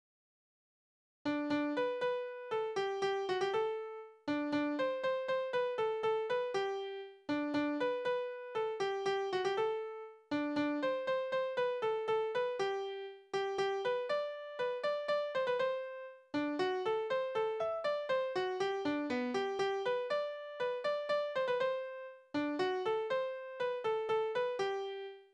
Balladen: Der edelmütige, siegreiche Feind
Tonart: G-Dur
Taktart: 3/4
Tonumfang: Oktave, Quarte